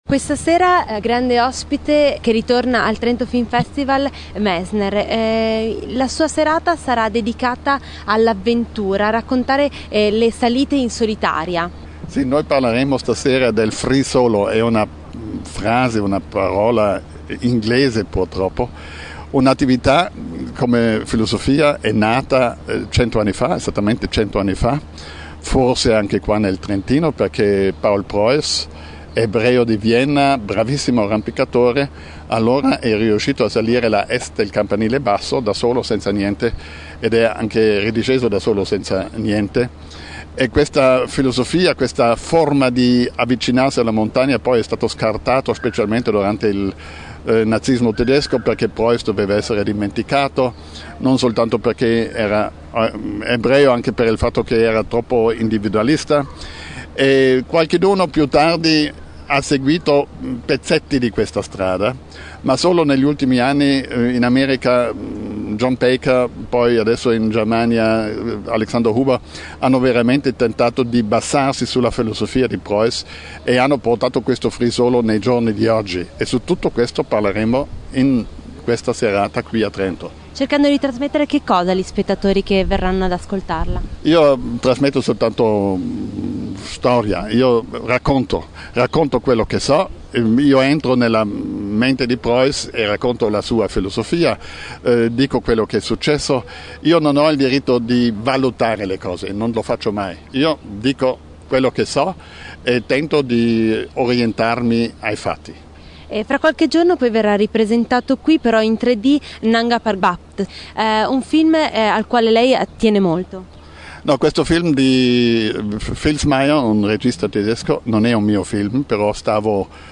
Si trattava di una domanda molto delicata perché per entrambi non fu un’esperienza positiva e come potrete cogliere, è evidente ancora quanto astio ci sia nella voce del grande alpinista.
podcast_Intervista_Messner_Nanga_3D.mp3